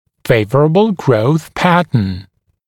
[‘feɪv(ə)rəbl grəuθ ‘pæt(ə)n][‘фэйв(э)рэбл гроус ‘пэт(э)н]благоприятный тип роста